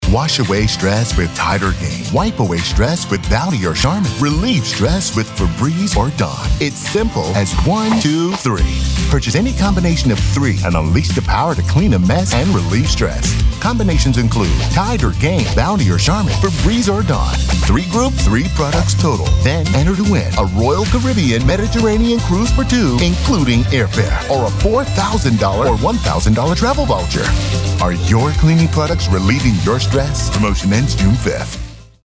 Male
American English (Native)
Assured, Authoritative, Bright, Bubbly, Confident, Cool, Corporate, Deep, Friendly, Reassuring, Sarcastic, Smooth, Streetwise, Warm, Versatile
Naturally deep, and deliciously rich voice that ads “flava” to your project.
Microphone: Sennheiser MKH 416, Nuemann TLM 103